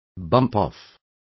Also find out how templar is pronounced correctly.